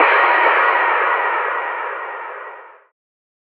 DDW4 SFX HIT.wav